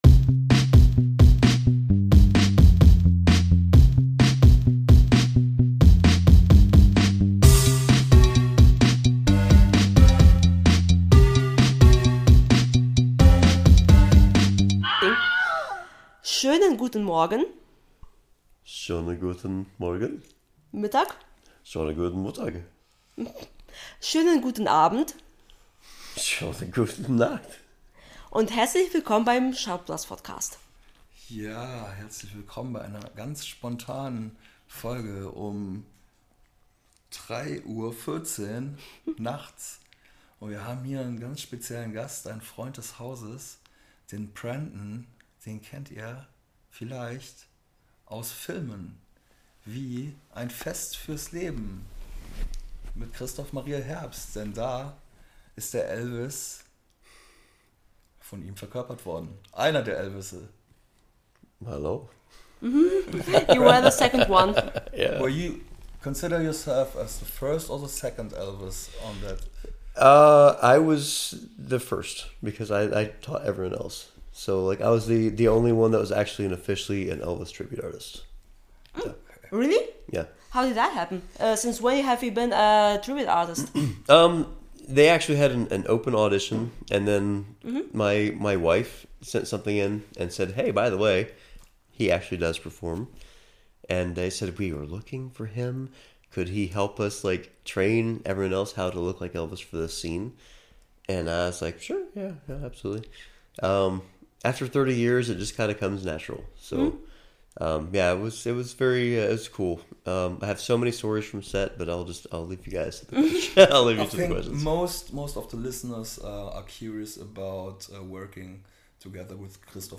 Very spontaneously and a little tipsy one night at 3 a.m. we decided to turn on the microphone!